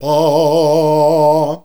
AAAAAH  A#.wav